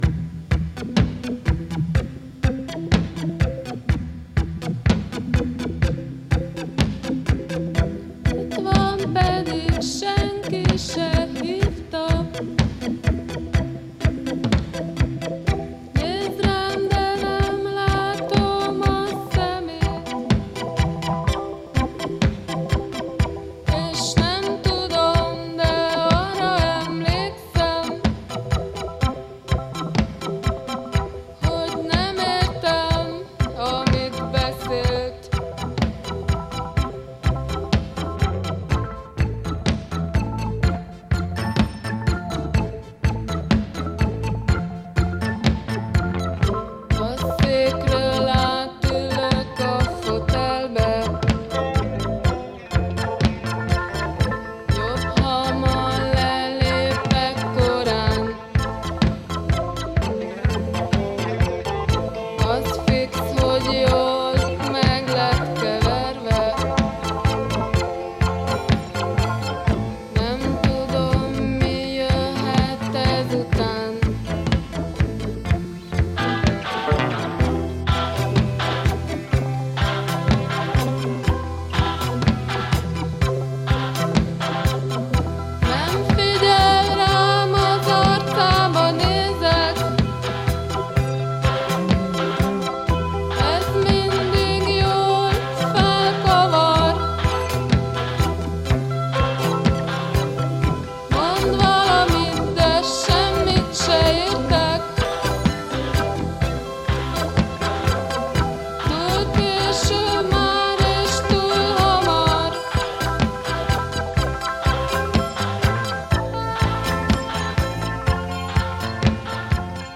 un groupe underground, d'avant-garde